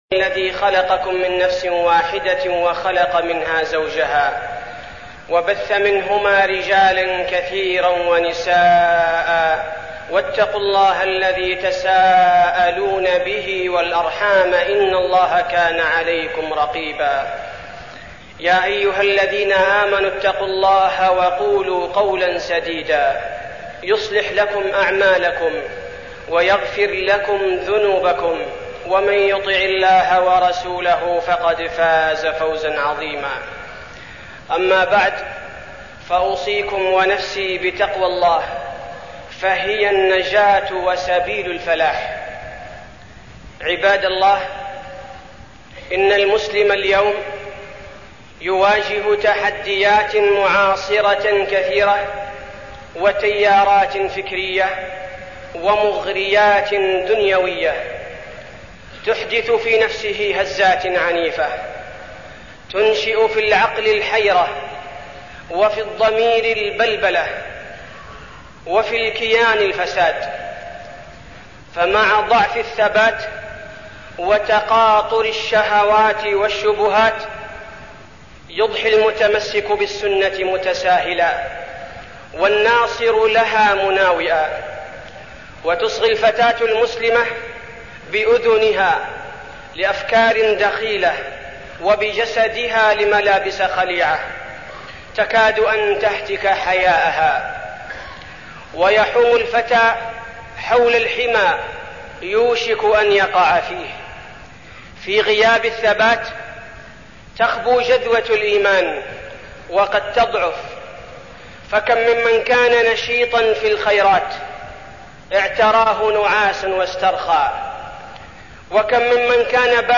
تاريخ النشر ٥ ربيع الثاني ١٤١٨ هـ المكان: المسجد النبوي الشيخ: فضيلة الشيخ عبدالباري الثبيتي فضيلة الشيخ عبدالباري الثبيتي الثبات على الدين